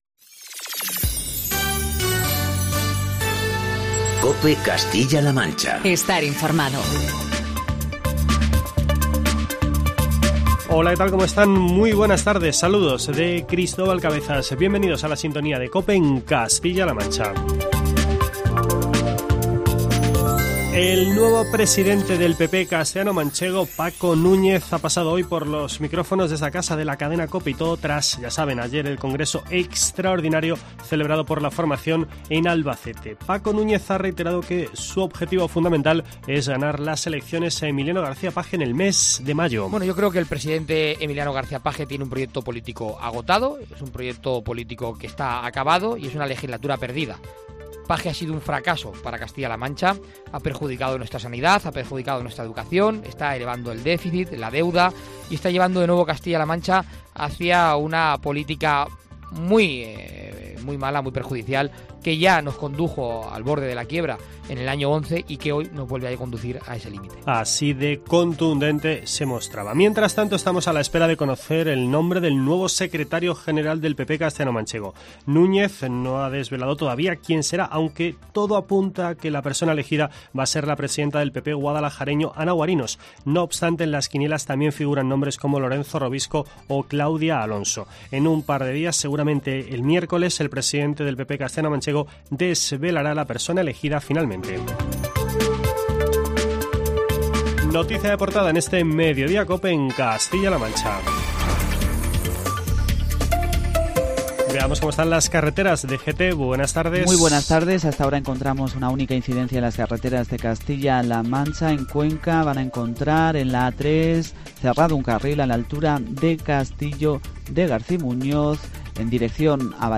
AUDIO: El nuevo presidente del PP de Castilla-La Mancha, Francisco Núñez, ha pasado por los micrófonos de COPE.